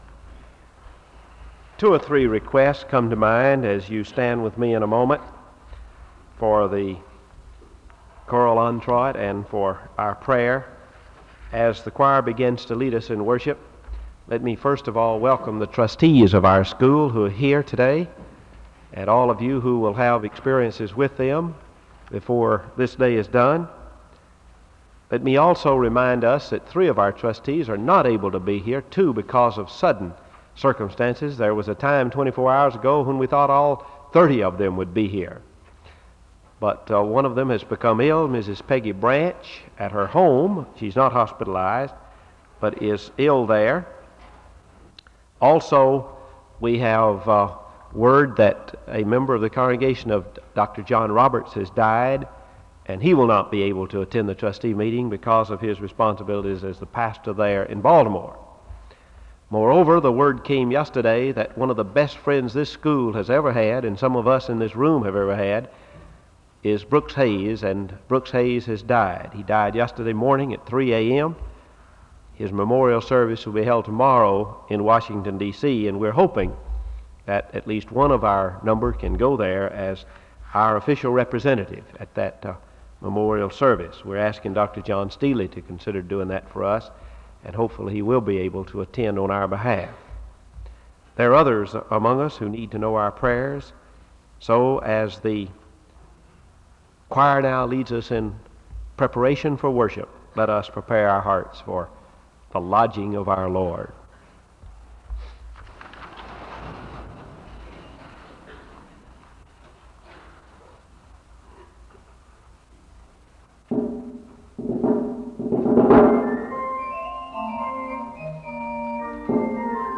The choir sings a song of worship (01:45-03:29).